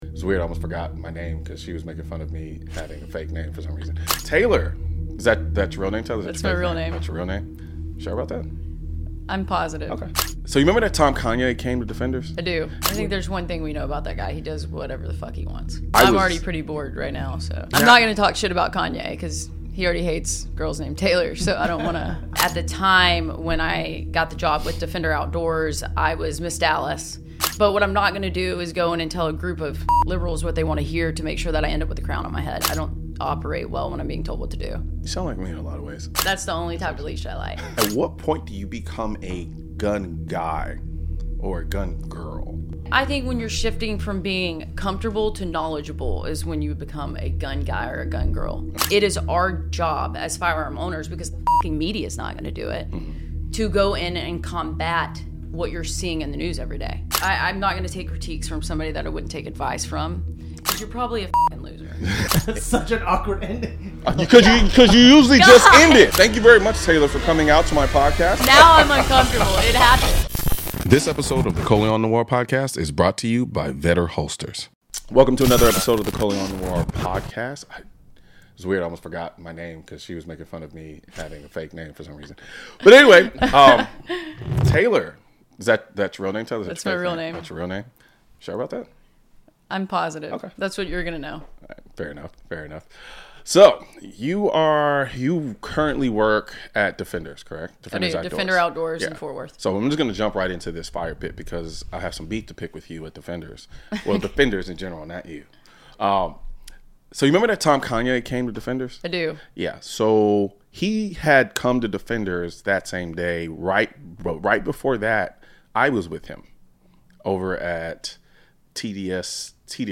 She talks about the fakeness of society 'norms' that she didn't want to deal with and found herself in the firearms industry. It's a no filter interview!